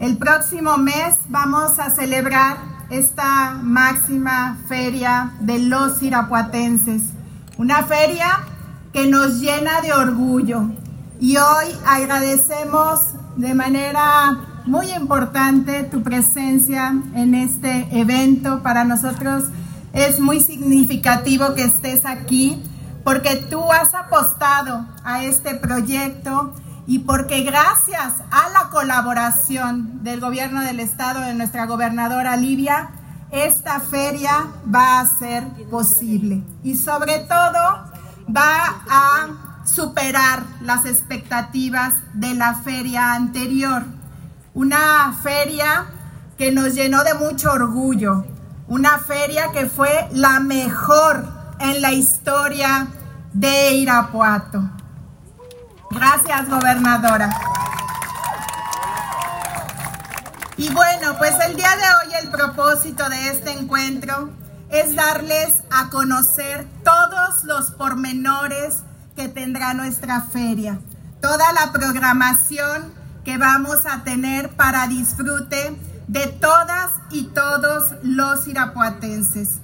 Lorena Alfaro García, Presidenta de Irapuato